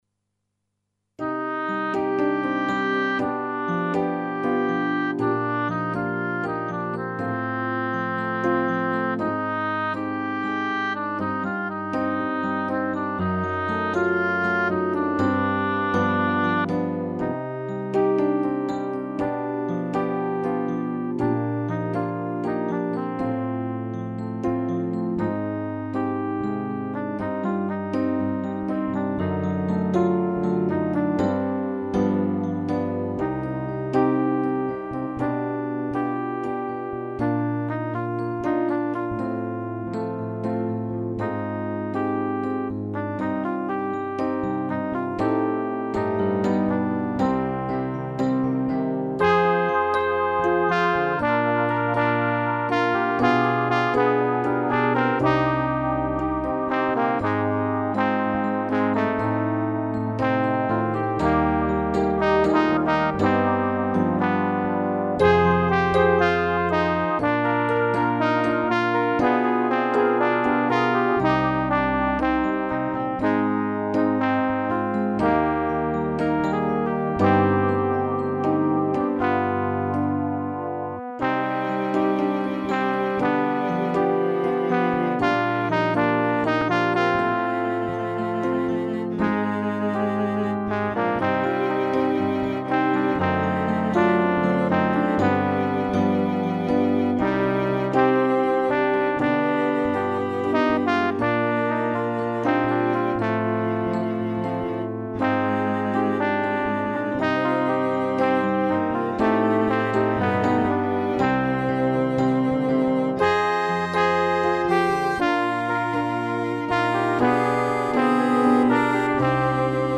pour choeur de femmes à deux voix
fichier de travail pour la voix 2
( seule voix d'accompagnement,
ici, avec accompagnement